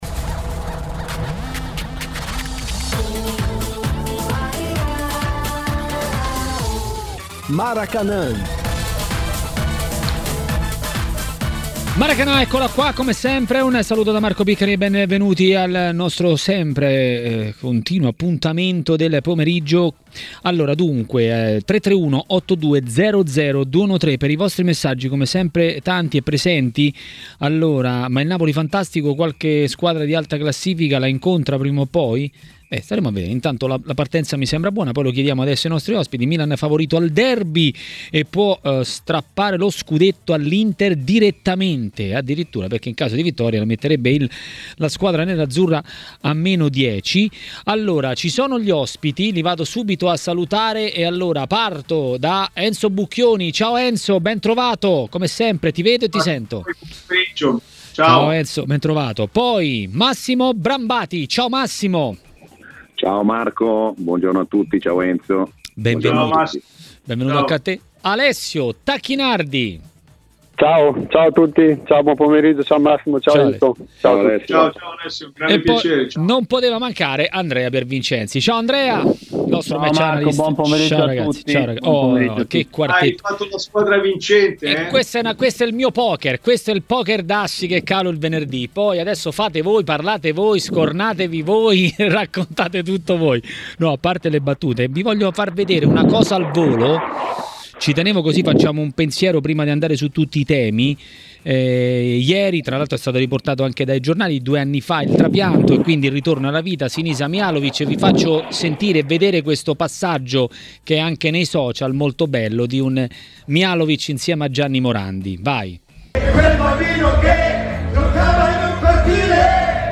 Roma, Juventus e non solo. A Maracanà, nel pomeriggio di TMW Radio, ha parlato il giornalista